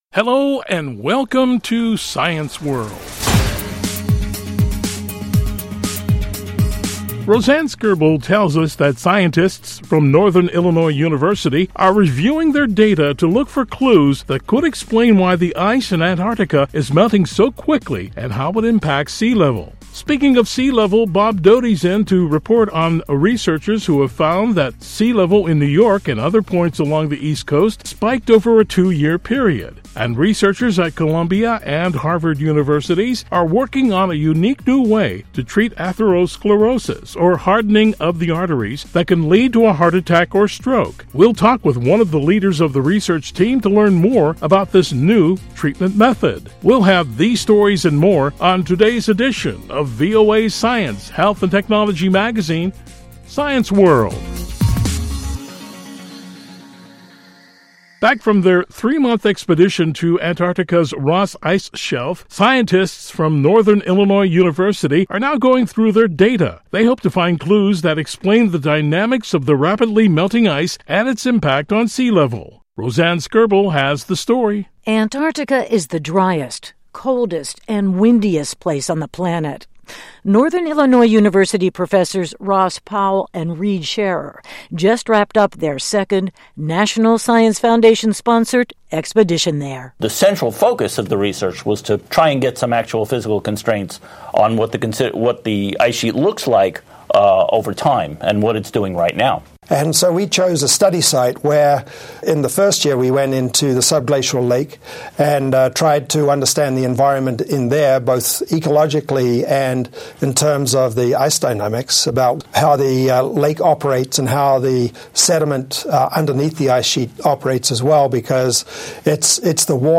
Researchers at Columbia and Harvard Universities are working on a unique new way to treat atherosclerosis, a hardening of the arteries that can lead to heart attack or stroke. We'll talk with one of the leaders of the research team to learn more about this new treatment method.